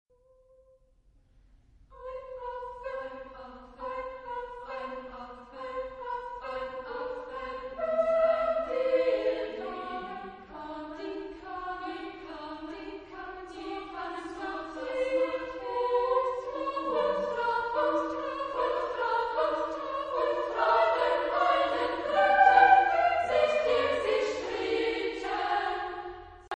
Genre-Style-Form: Choral song ; Cycle ; Secular
Type of Choir: SSA  (3 women voices )
Tonality: free tonality